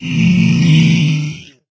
zpigdeath.ogg